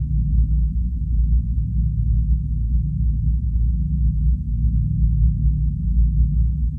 pulsing_loop.wav